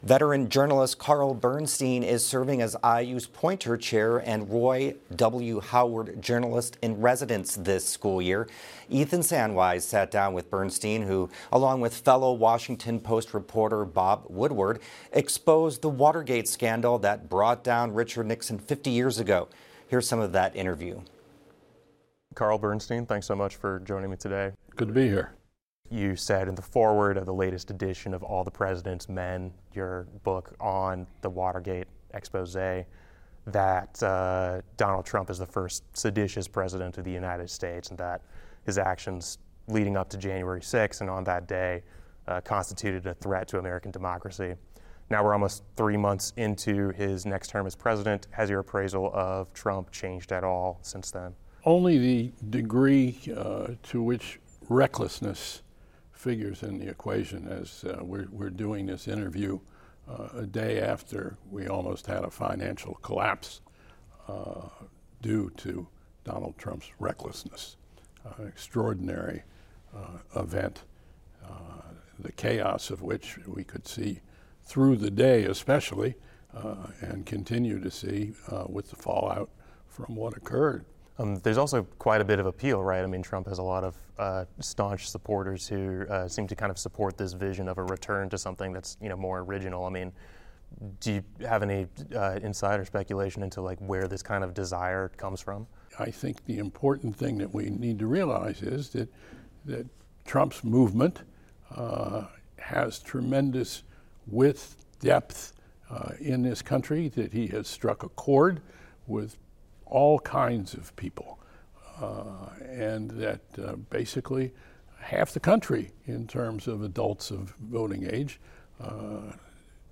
Read more: Nobel prize winner Maria Ressa on journalism and attacks on democracy This Q&A was conducted on April 10 and has been edited for clarity.